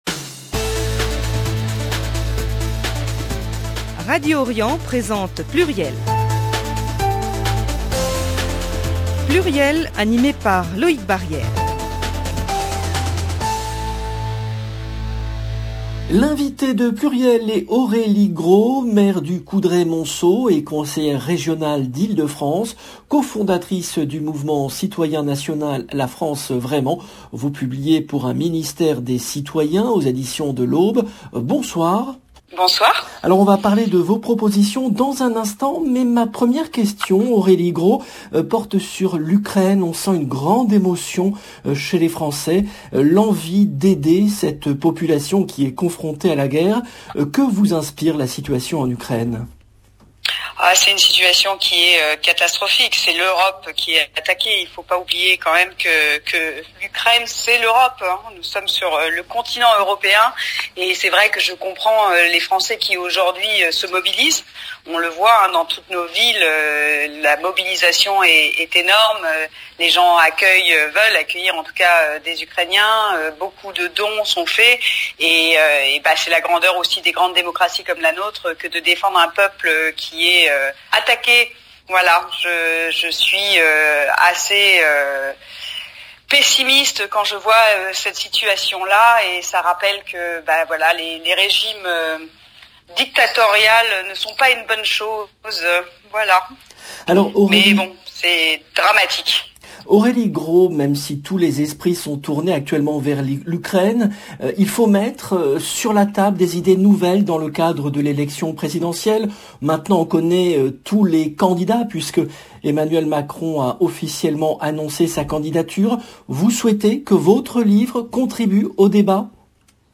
le rendez-vous politique du lundi 7 mars 2022 L’invitée de PLURIEL est Aurélie Gros , maire du Coudray-Montceaux et conseillère régionale d’Île-de-France.